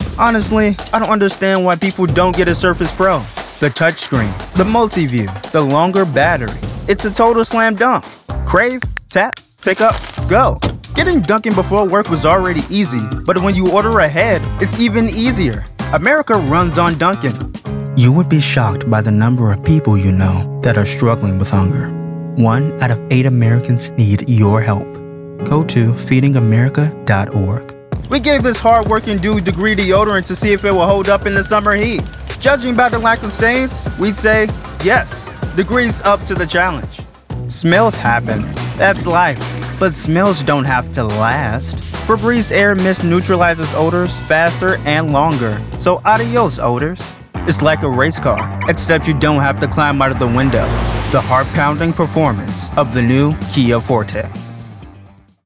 Commercial Demo #1
Southern English, British English, General north American English , African American/Midwest
Teen
Young Adult